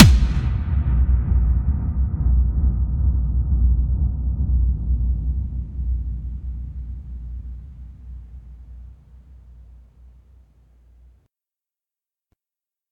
kick.ogg